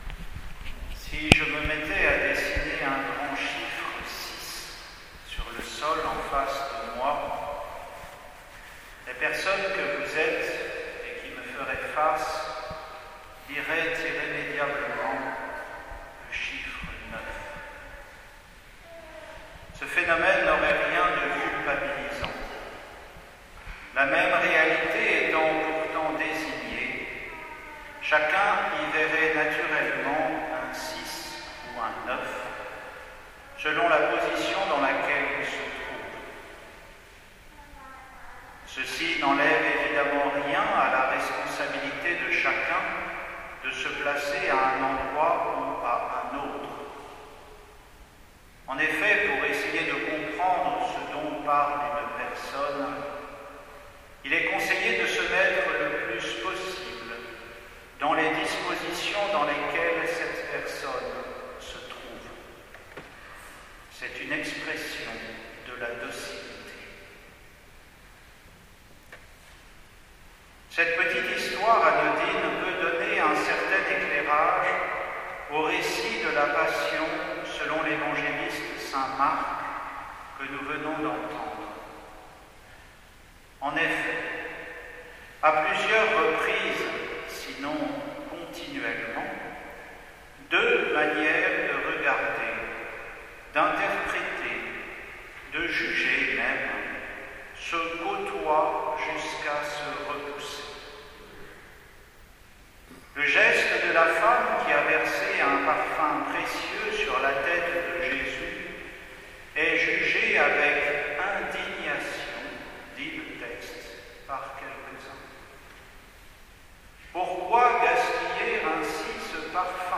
Homélie pour le dimanche des Rameaux 2021